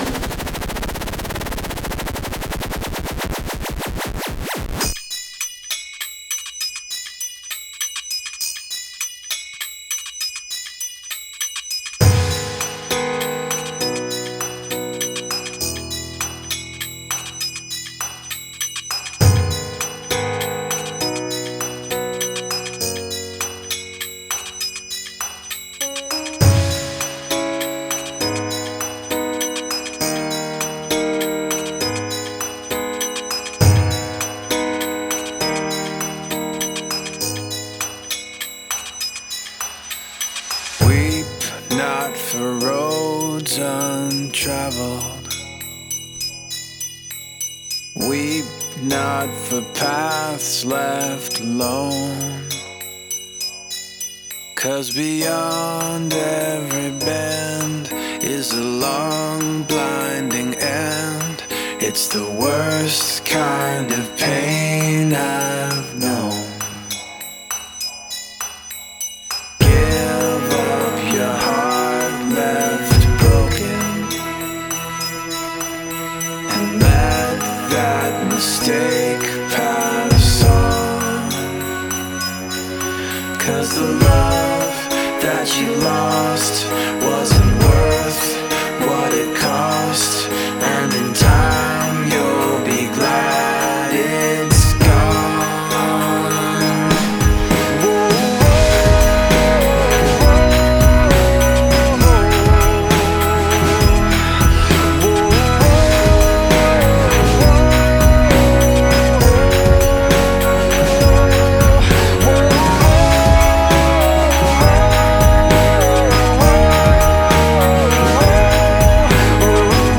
سبک موسیقی راک